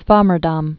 (svämər-däm), Jan 1637-1680.